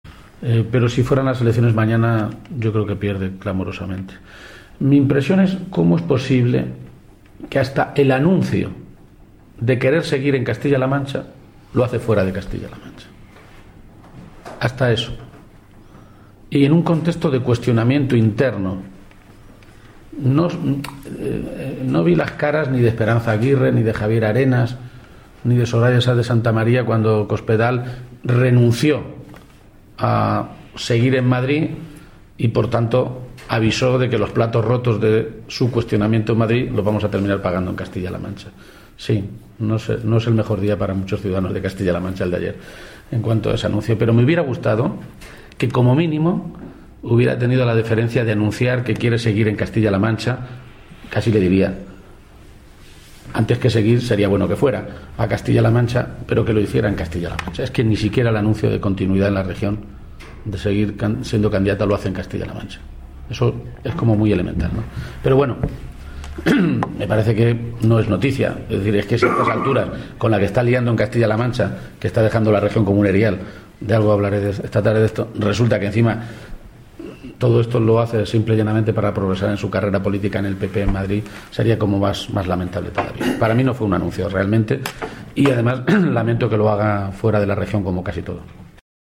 García-Page se pronunciaba de esta manera en una rueda de prensa en la sede provincial del PSOE de Jaén, ciudad a la que ha acudido invitado para participar en una charla-coloquio sobre los retos de la política actual y en la que va a hablar, de manera específica, de los modelos políticos regionales de Andalucía y Castilla-La Mancha.